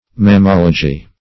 Mammology \Mam*mol"o*gy\, n.
mammology.mp3